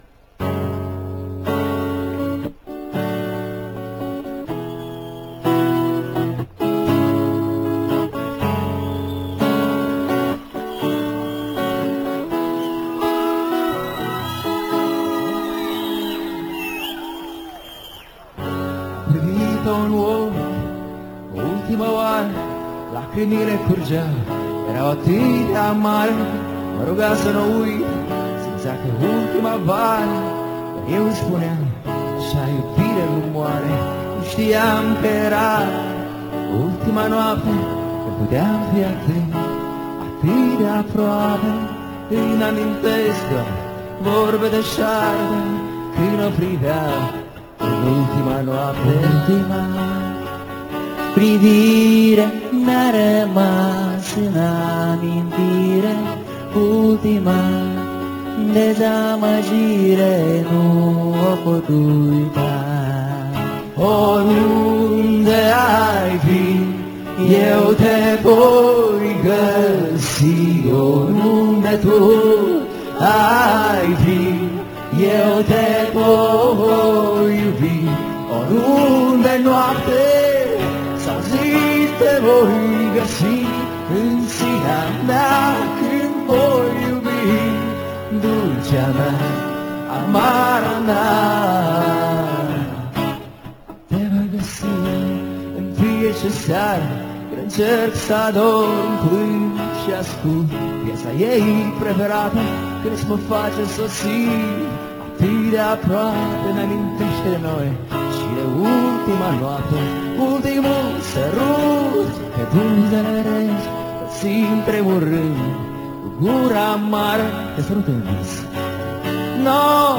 Лайвы
(исполнение песен вживую):